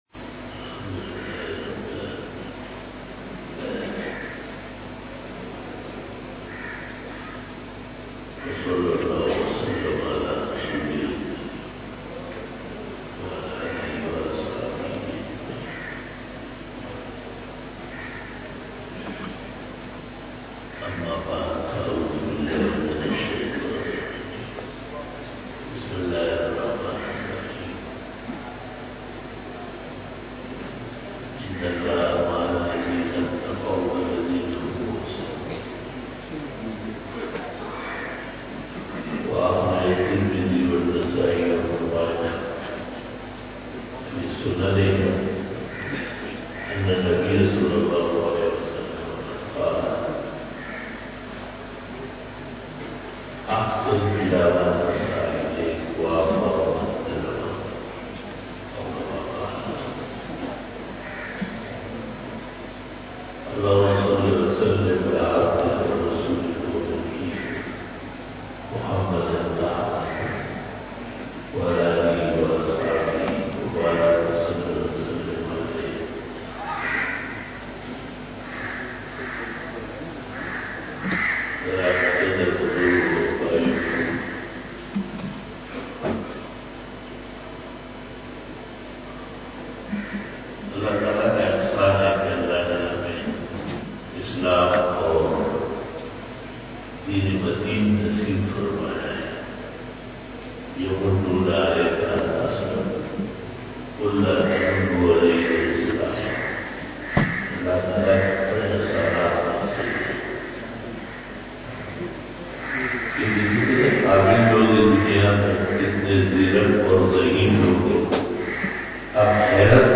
51 BAYAN E JUMA TUL MUBARAK (21 December 2018) (13 Rabi us Sani 1440H)
Khitab-e-Jummah 2018